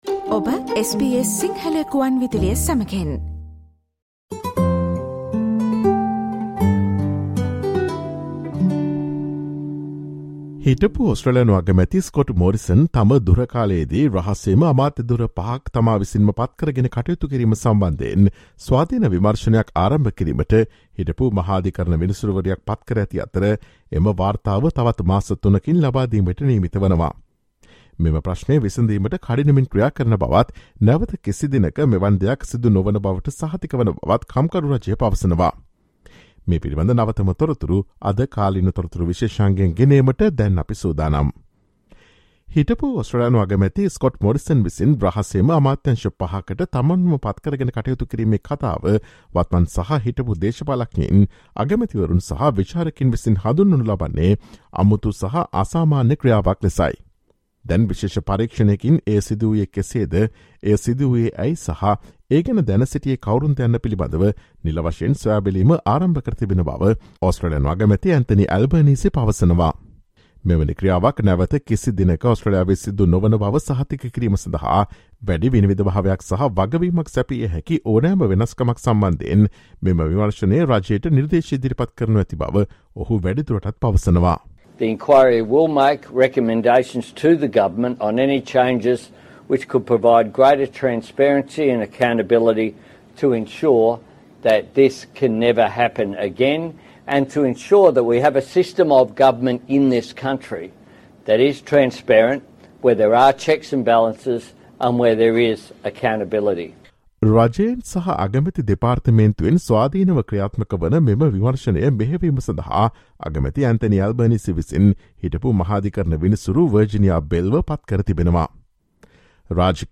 Former High Court judge Virginia Bell is to lead the inquiry into Scott Morrison's secret ministries and is due to hand down her findings in just three months. Listen to the SBS Sinhala Radio's current affairs feature broadcast on Friday 02 September.